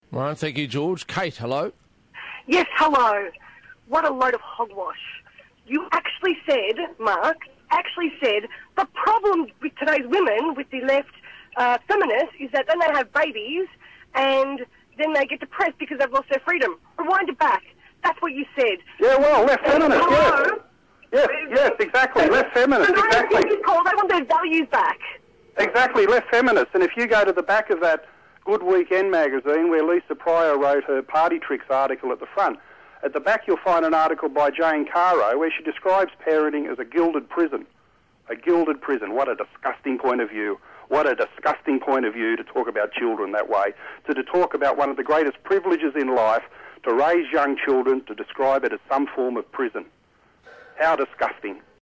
Mark Latham takes on 3AW caller